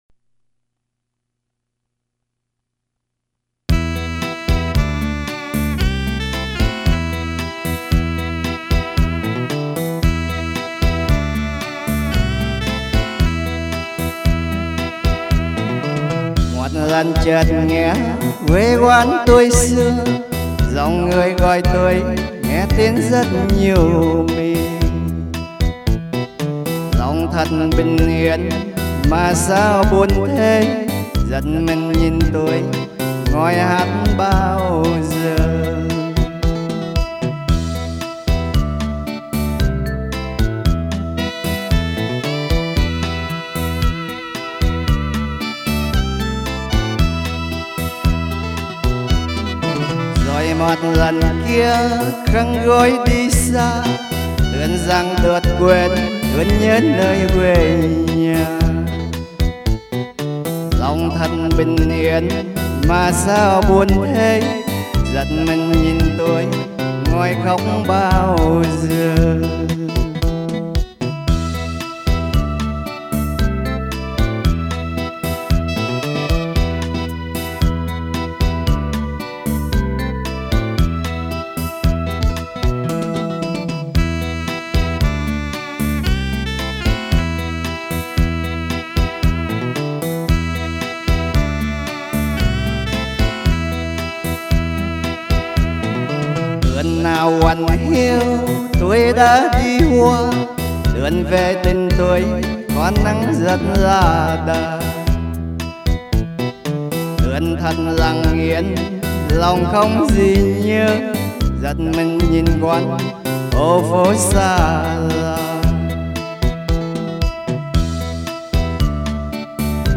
ca khúc wê hương